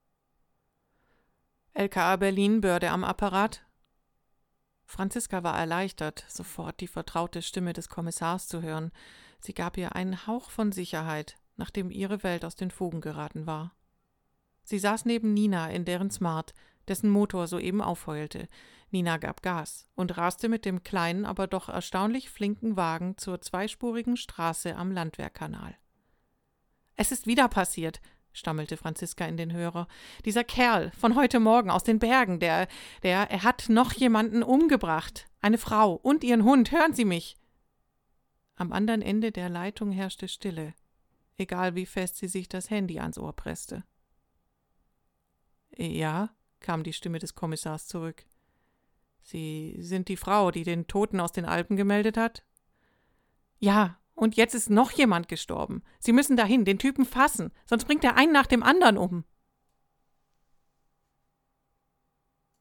sprecherin
Demo Aufnahmen
Krimi
Krimi_02.mp3